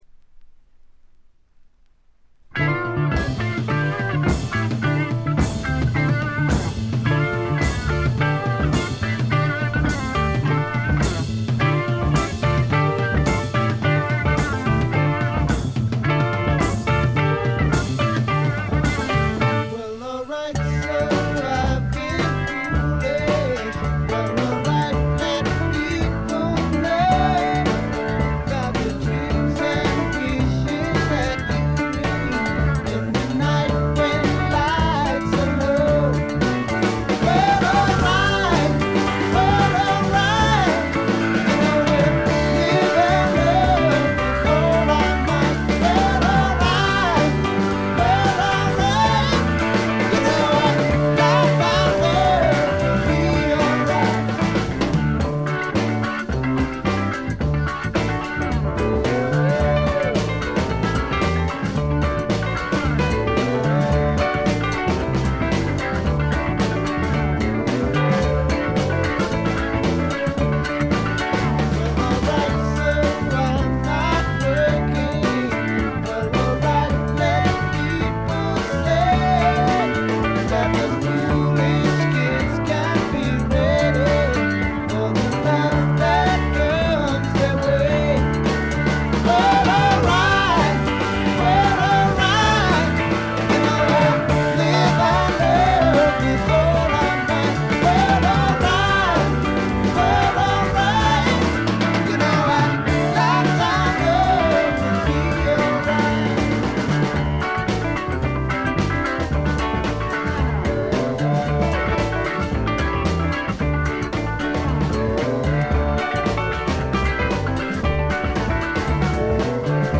軽微なチリプチが少し聴かれる程度。
試聴曲は現品からの取り込み音源です。